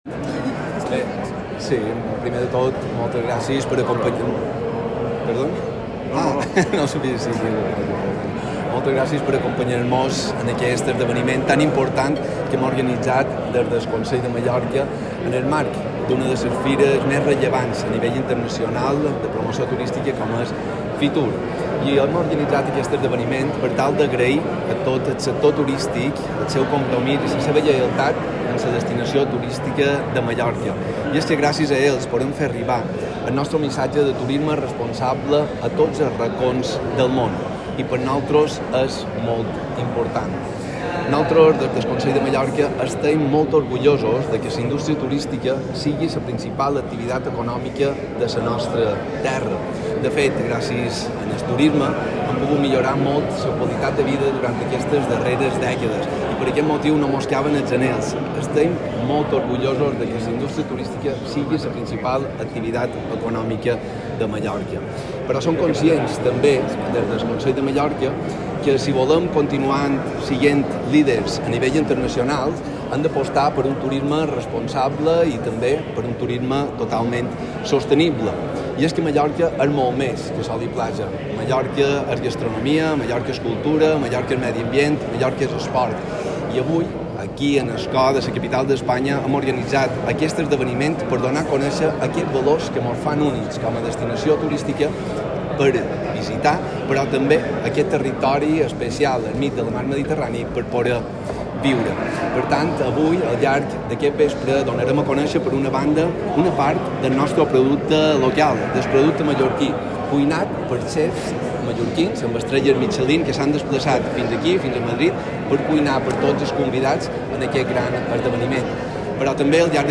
Declaraciones del presidente del Consell de Mallorca, Llorenç Galmés.
fitur_declaracions-president-galmes